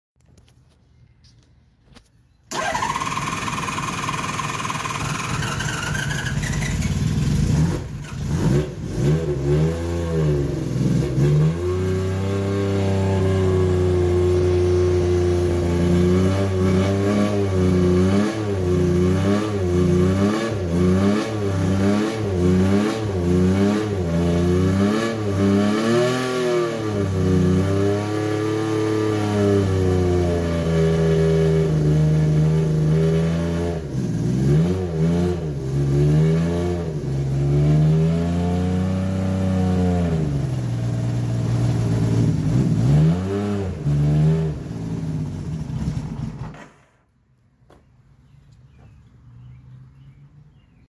81 Honda CBX first start sound effects free download